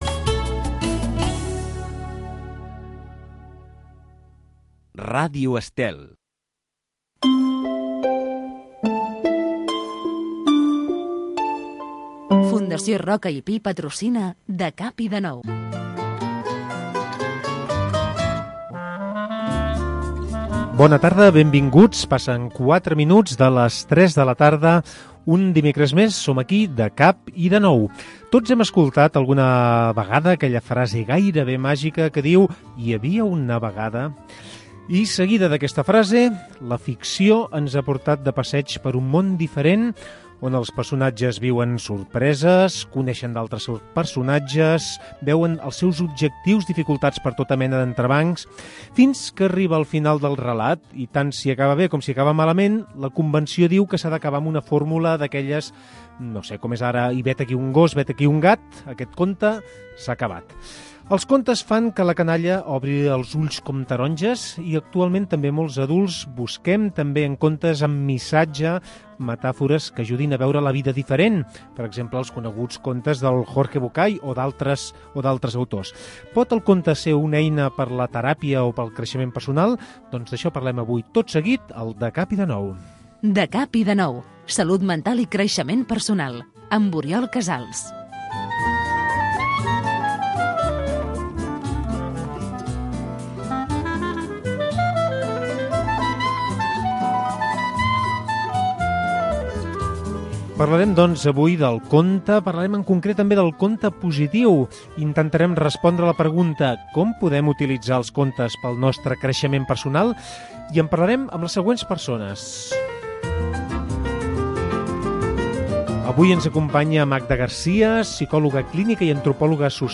Mitjançant entrevistes i seccions fixes, anem descobrint com, malgrat les dificultats, la vida comença cada dia… de cap i de nou.